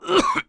cough2.mp3